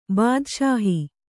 ♪ bādaṣāhi